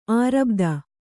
♪ ārabdha